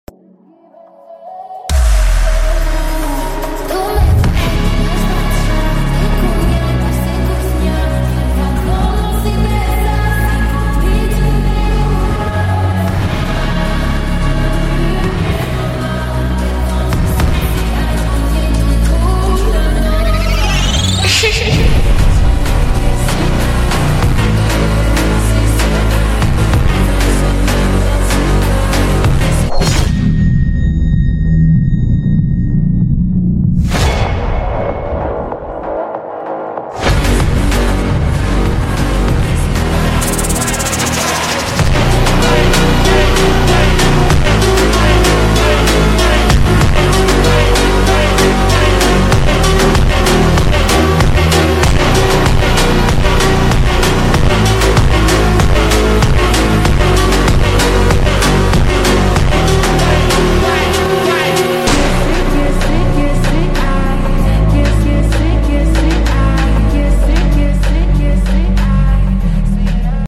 FUNK (Slowed)